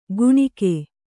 ♪ guṇike